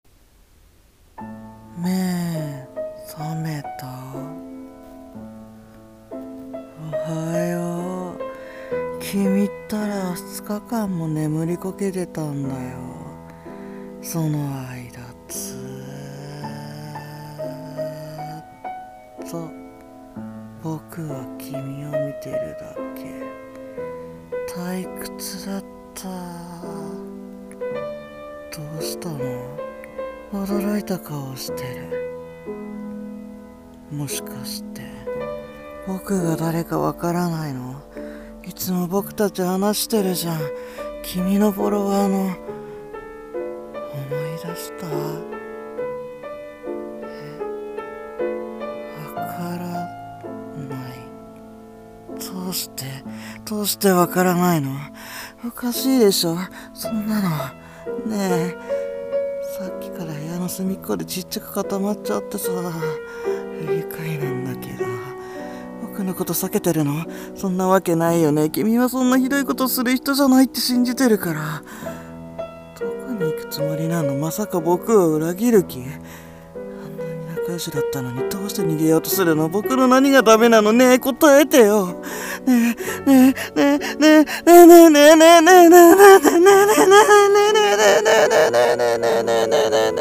ヤンデレ台本『監禁狂愛ネットストーカー』